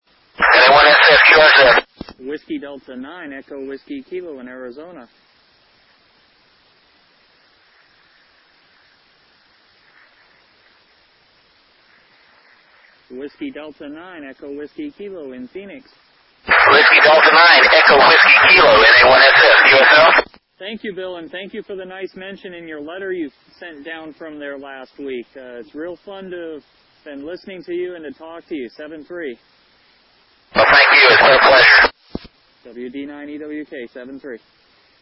voice contact with Bill McArthur at NA1SS, using an Icom IC-T7H HT at 5W with an Arrow Antennas handheld 2m/70cm Yagi.
standing in downtown Phoenix (grid DM33xl) for this contact.